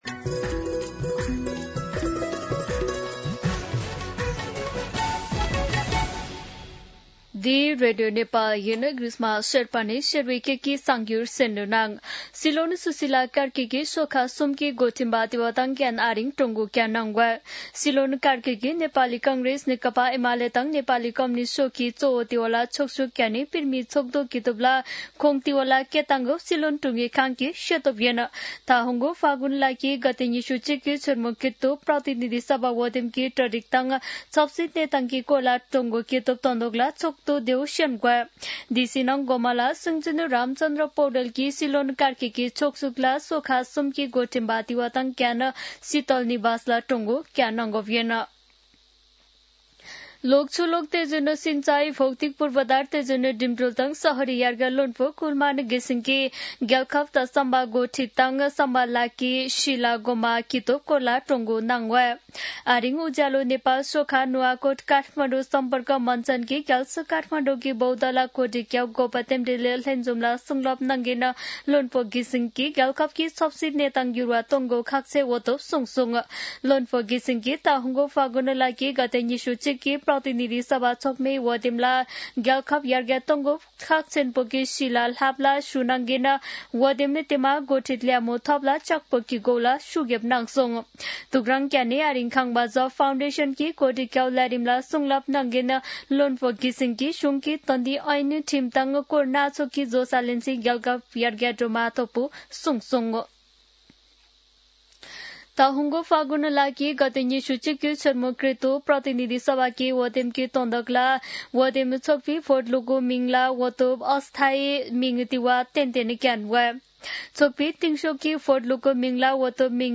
शेर्पा भाषाको समाचार : १२ पुष , २०८२
Sherpa-News-3.mp3